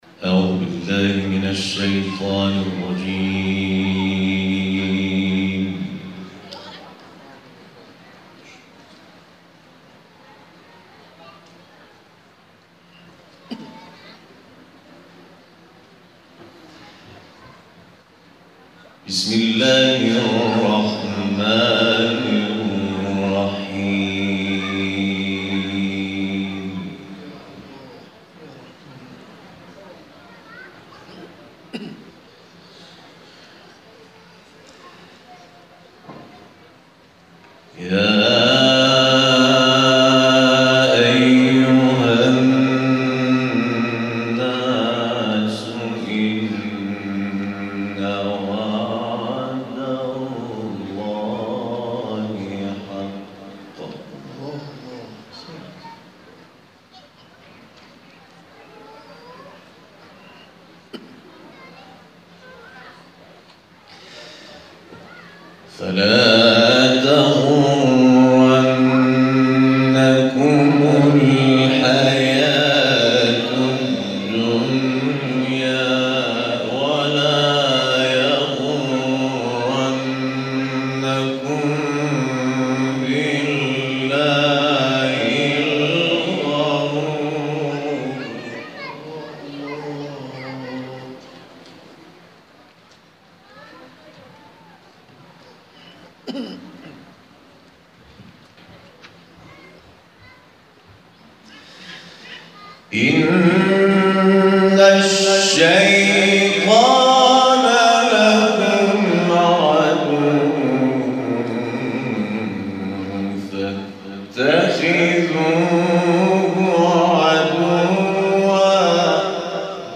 جدیدترین تلاوت
گروه فعالیت‌های قرآنی: محفل انس باقرآن کریم، شب گذشته در مجتمع فرهنگی سرچشمه برگزار شد.